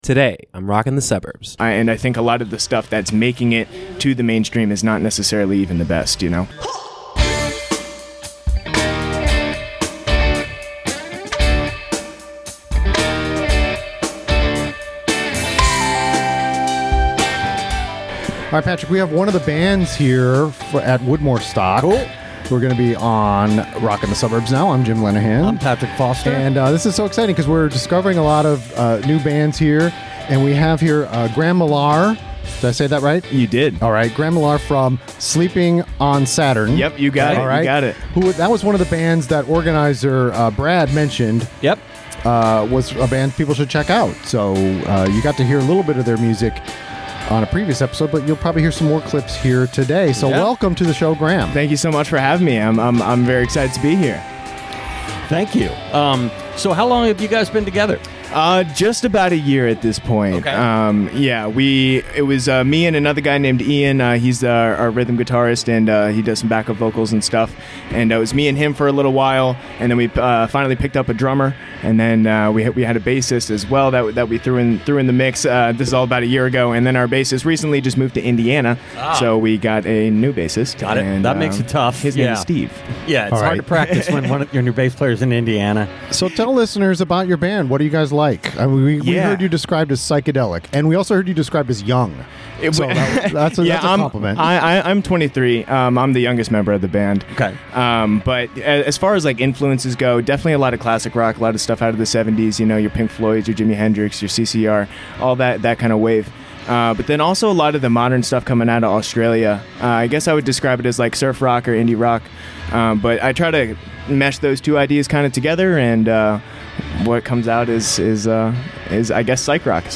Live from Woodmoorstock with Sleeping on Saturn